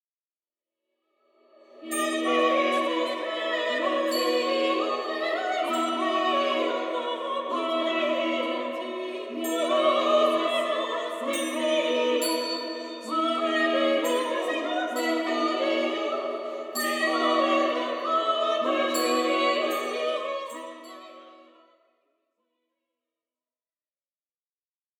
célèbre ensemble vocal
les voix des chanteuses